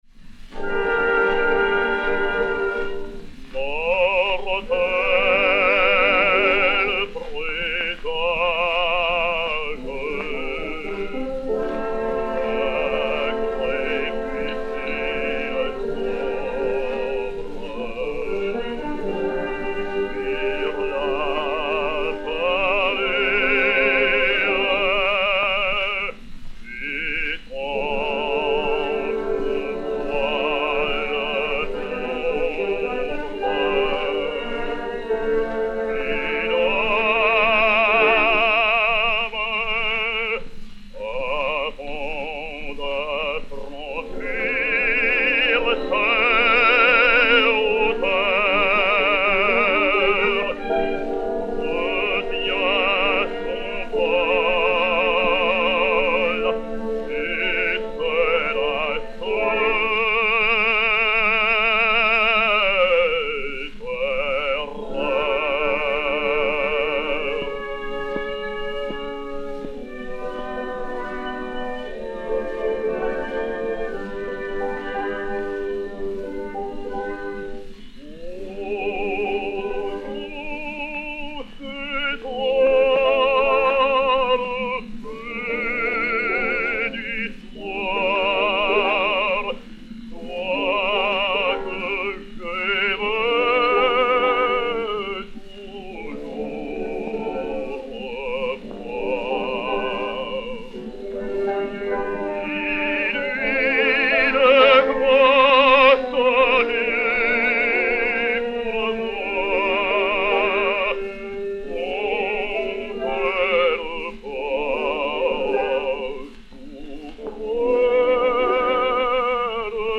Daniel Vigneau (Wolfram) et Orchestre